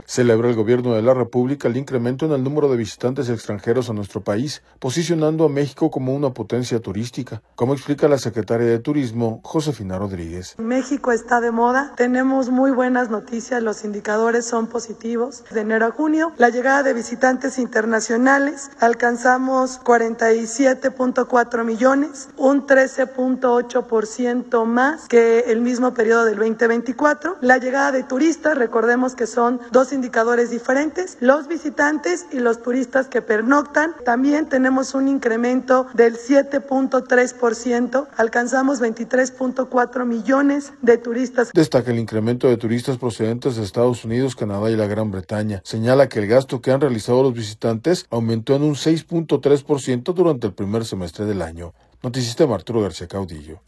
Celebra el Gobierno de la República el incremento en el número de visitantes extranjeros a nuestro país, posicionando a México como una potencia turística, como explica la secretaria de Turismo, Josefina Rodríguez.